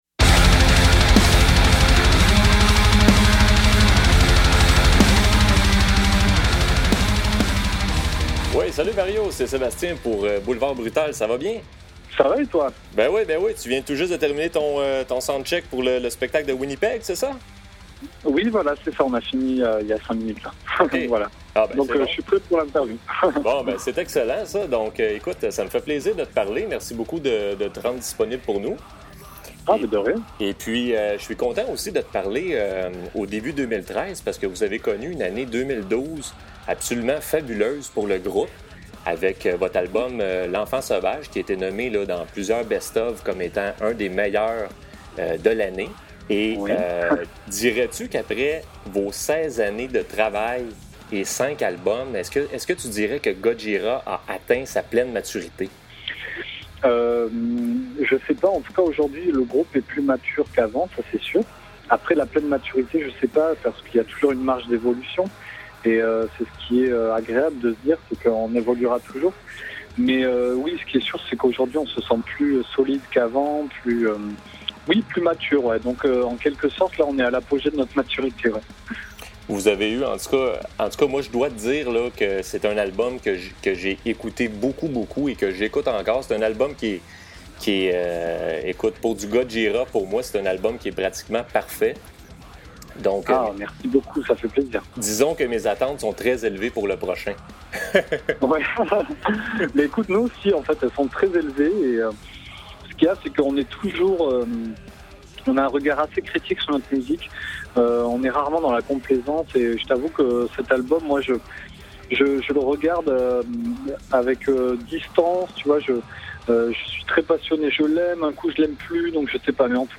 Entrevue avec Mario Duplantier de GOJIRA
Voici donc l’entrevue que j’ai réalisée avec Mario juste avant qu’il monte sur scène à Winnipeg.